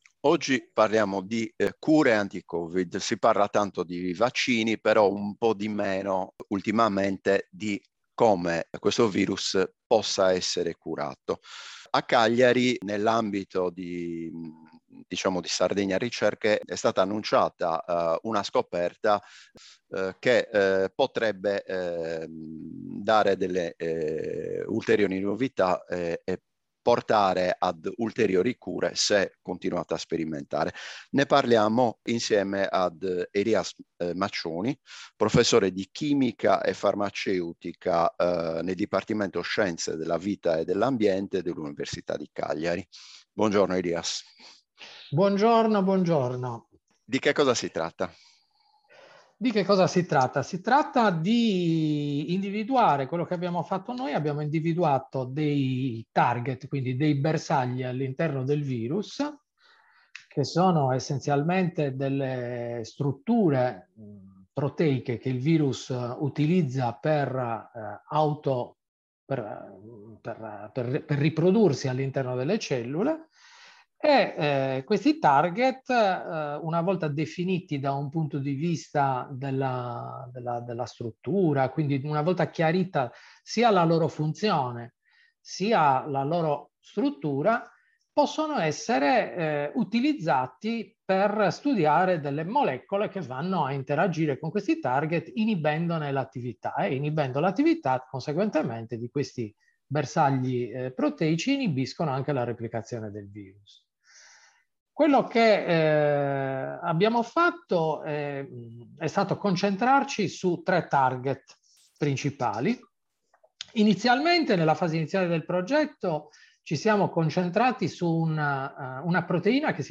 Farmaci anti Covid: Università di Cagliari in prima linea | Audiointervista